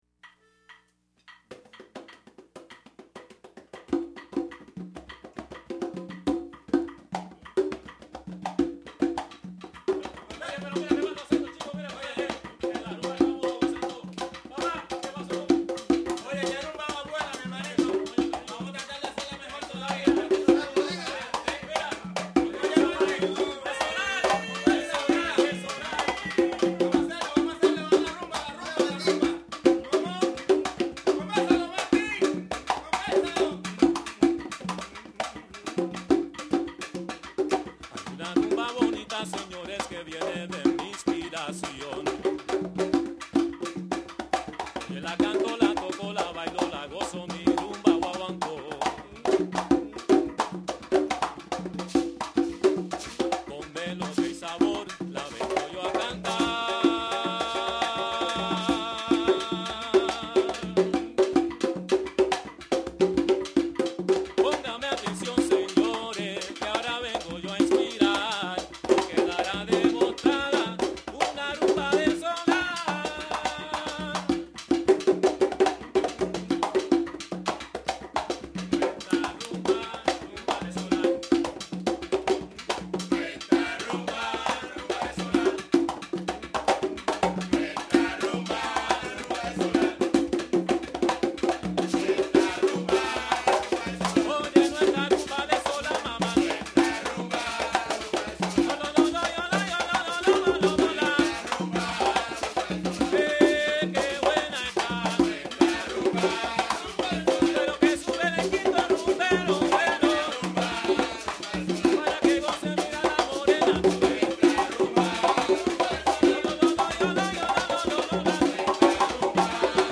This is a rumba guaguancó
clave
lead vocal
chorus/maracas